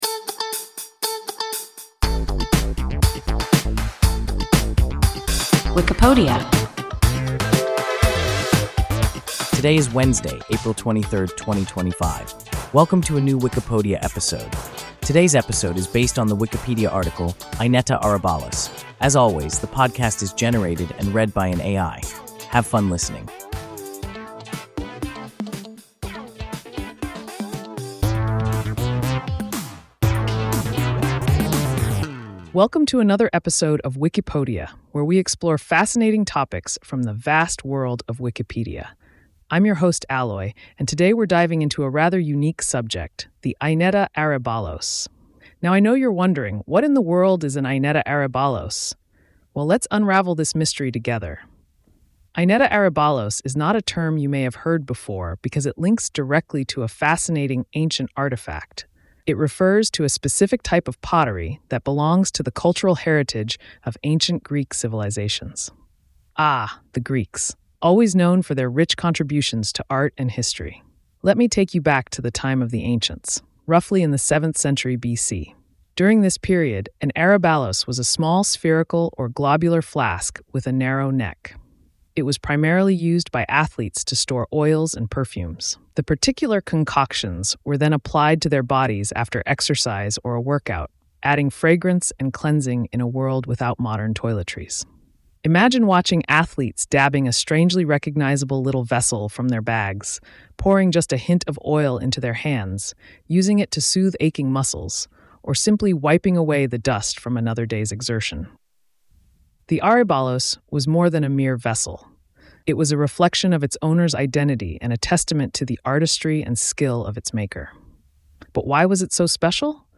Aineta aryballos – WIKIPODIA – ein KI Podcast